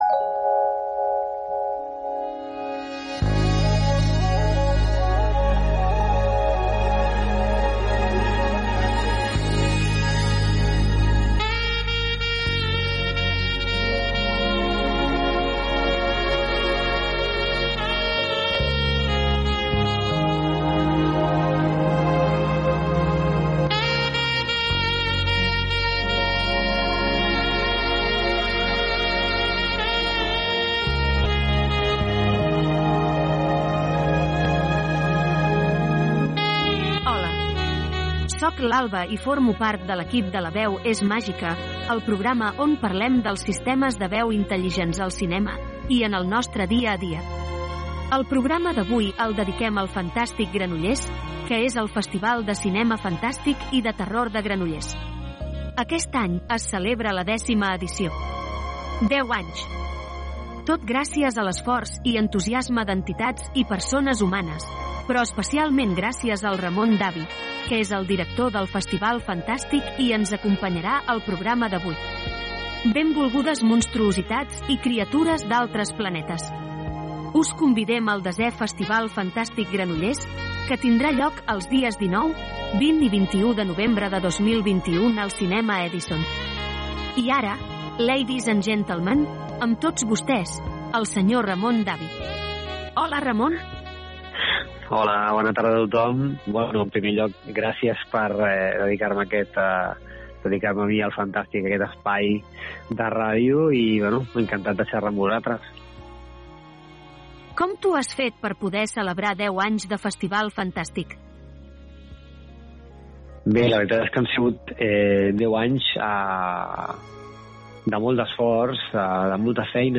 Divulgació
Alba (veu sintètica en català)
Primer programa de ràdio presentat íntegrament per una veu sintètica en català.